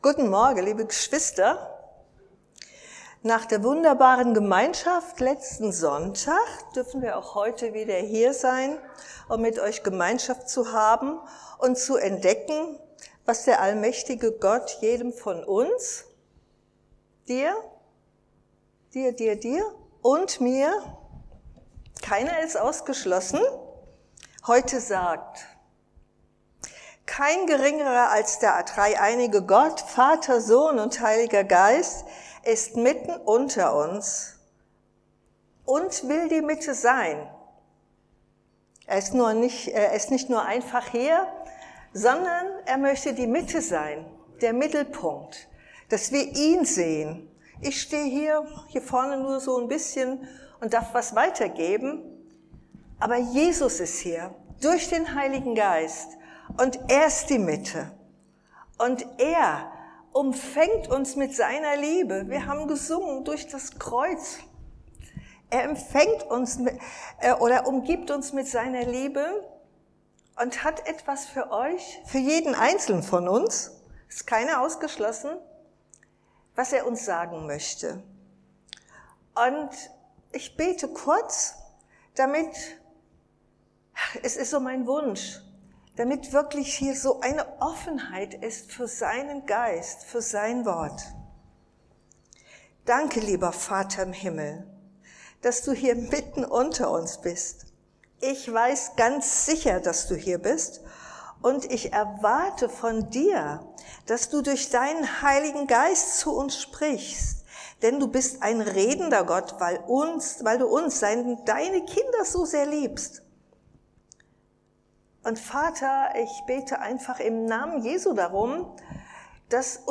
Tim.1:7; Johannes 14:15-17 Dienstart: Predigt Die Wiederkunft Jesu steht unmittelbar bevor.